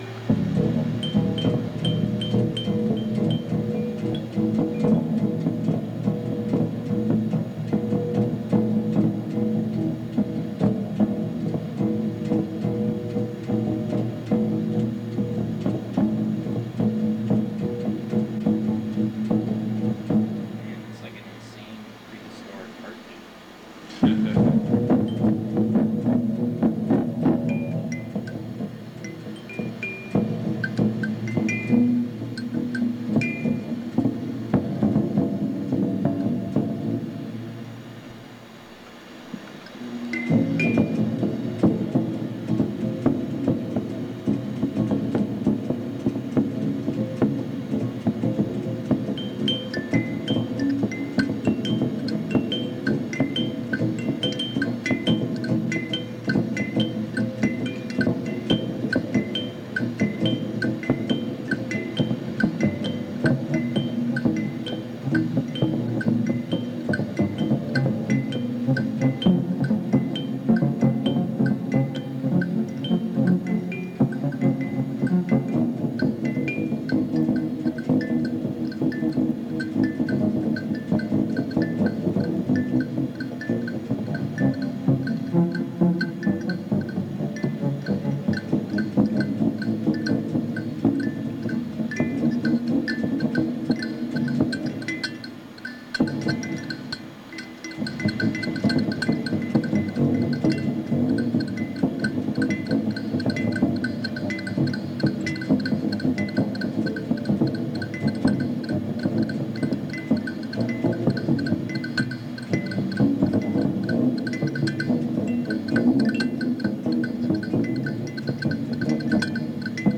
experimental ambient